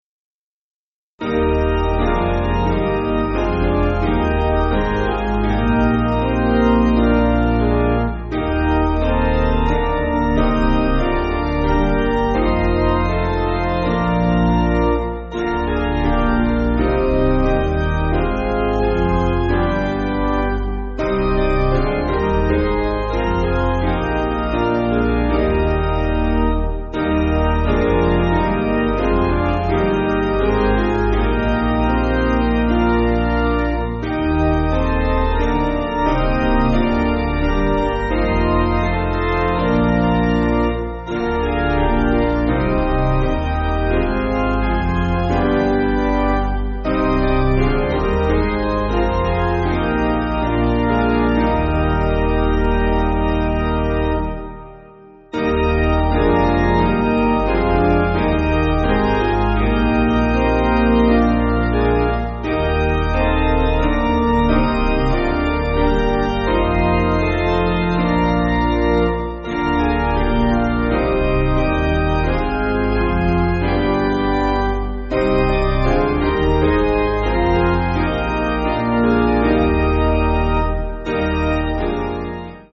Basic Piano & Organ
(CM)   2/Eb